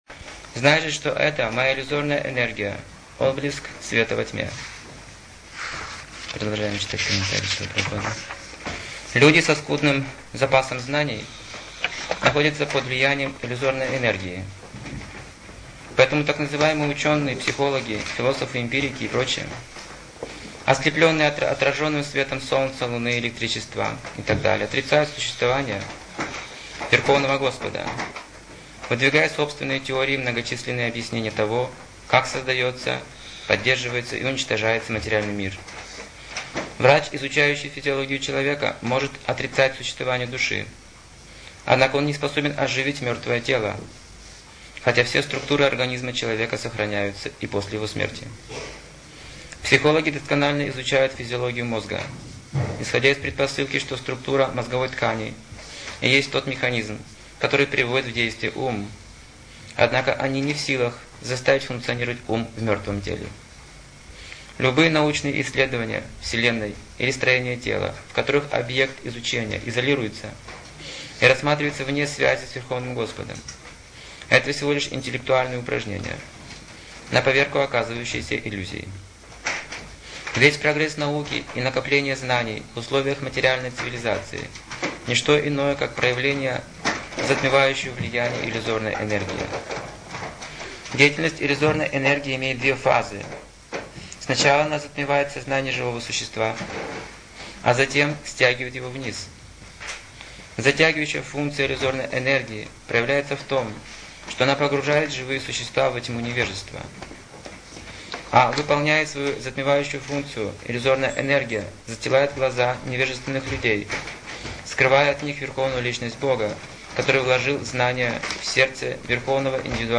Темы, затронутые в лекции: Влияние иллюзорной энергии Сат чит ананда Гуна невежества Уровни сознания Духовный путь Свойство сатва гуны 54 качества ложного эго Правильное понимание духовной практики Желания живого существа